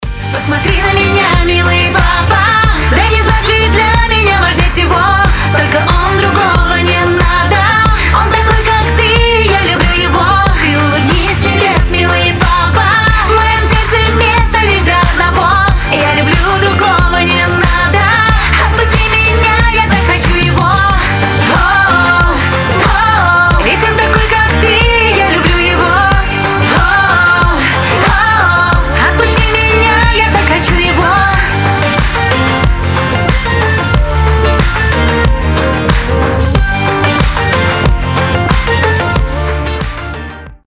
• Качество: 320, Stereo
поп
громкие
грустные